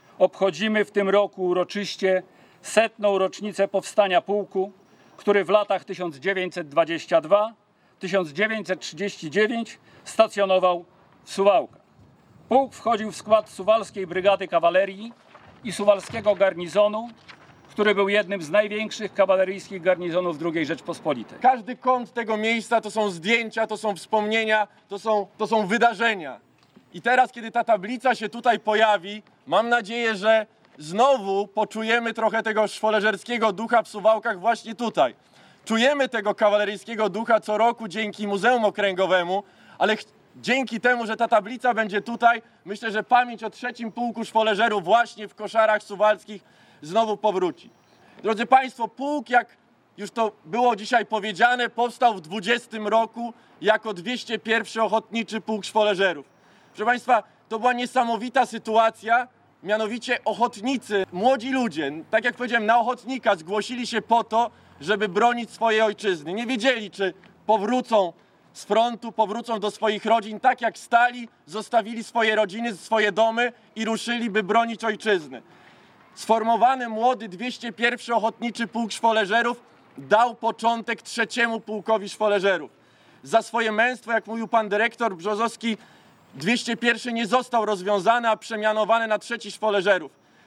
Swoje stulecie obchodził w sobotę (22.08.20) w Suwałkach 3. Pułk Szwoleżerów Mazowieckich.